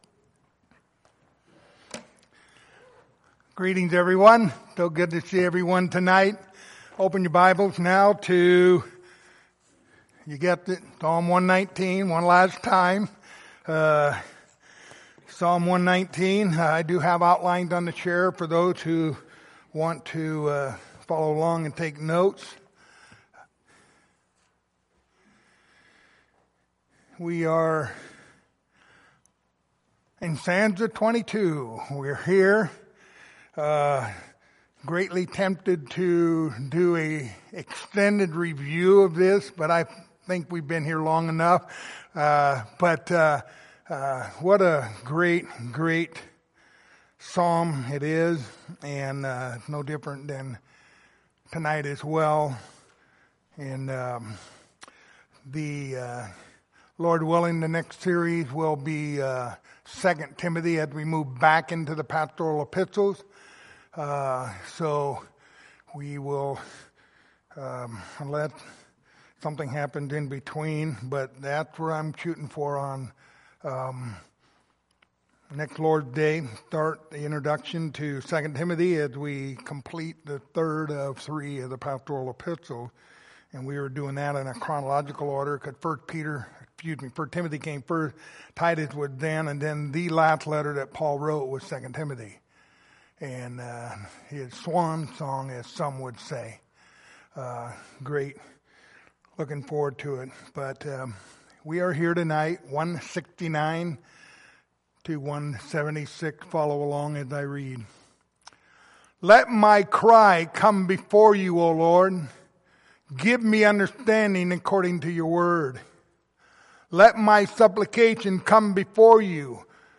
Passage: Psalm 119:169-176 Service Type: Sunday Evening